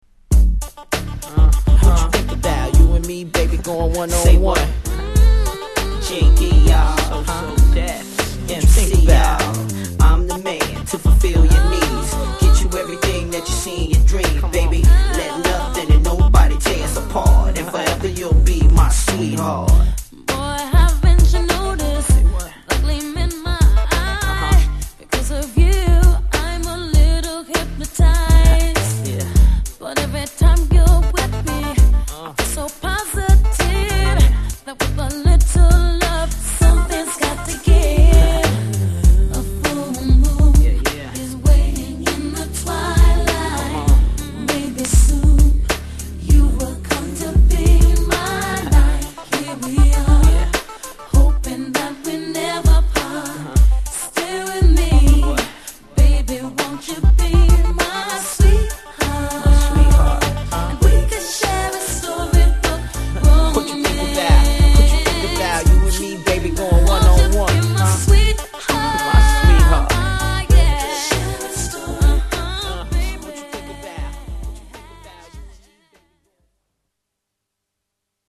Genre: #R&B